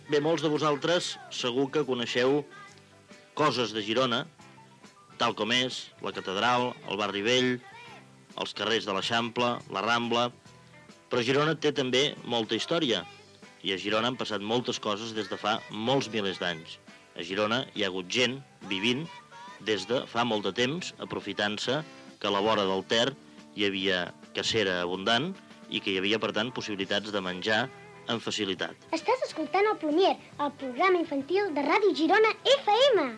El polític Joaquim Nadal explica un conte i indicatiu del programa
Infantil-juvenil